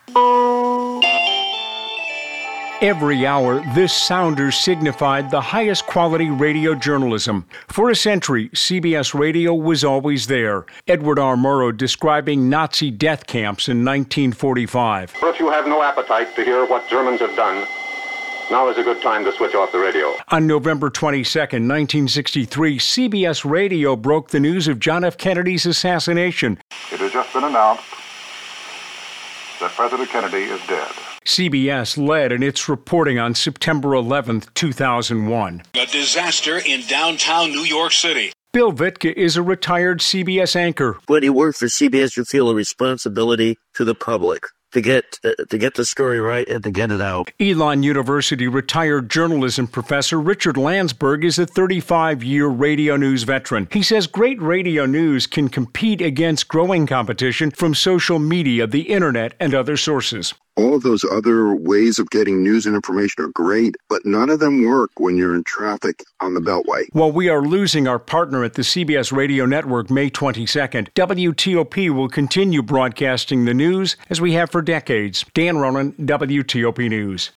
1-cbs-radio-farewell-dro.mp3